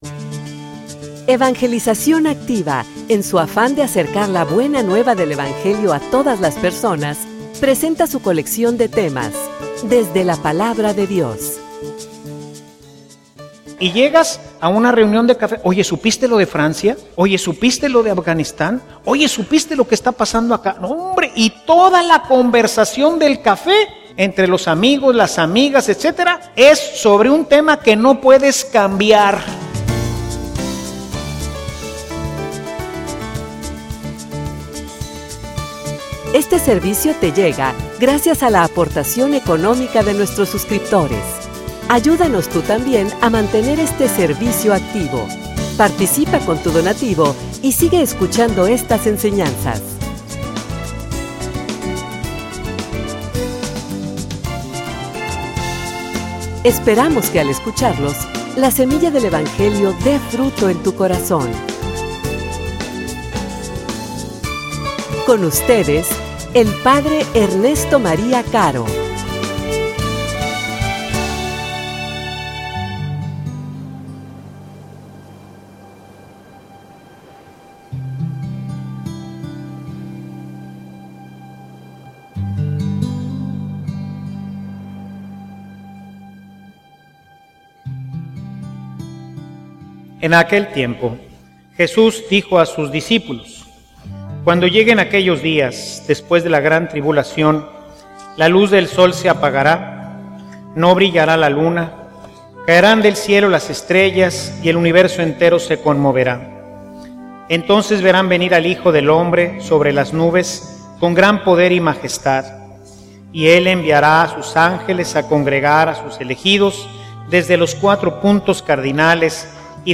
homilia_No_te_preocupes_ocupate.mp3